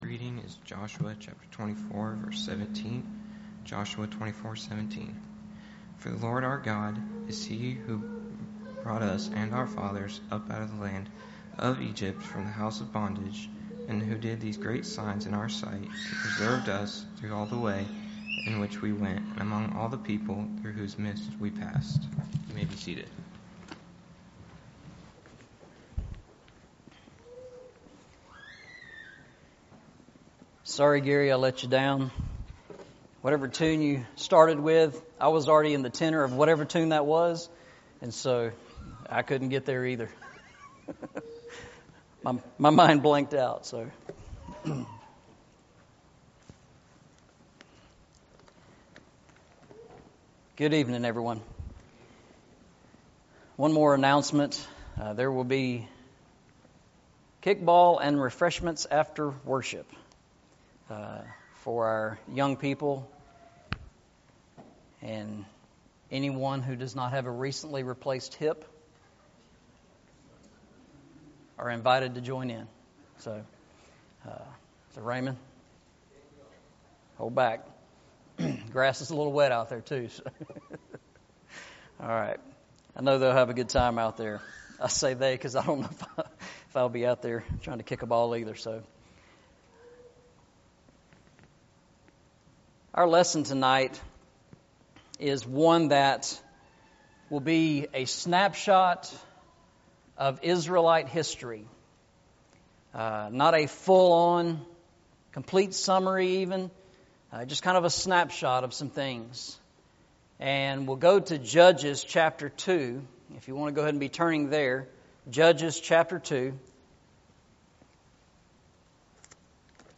Eastside Sermons
Joshua 24:17 Service Type: Sunday Evening « That They May See Your Good Works Jesus Christ